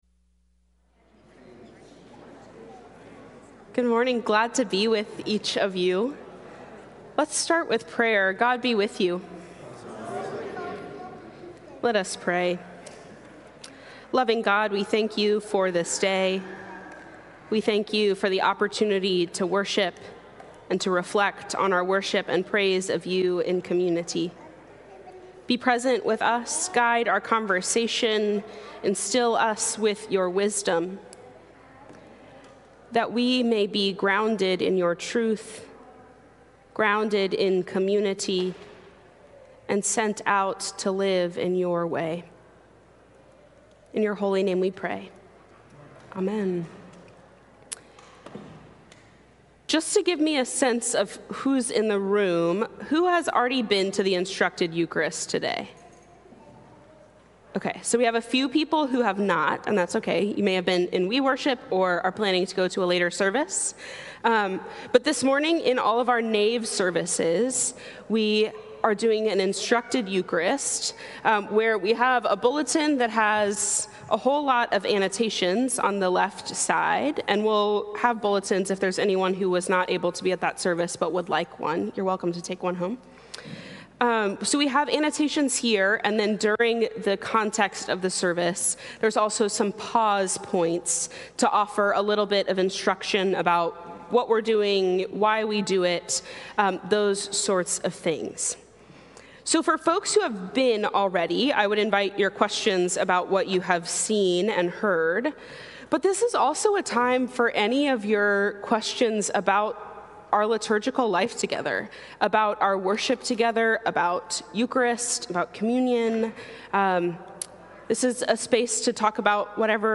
Sunday Forum from St. Columba's in Washington, D.C.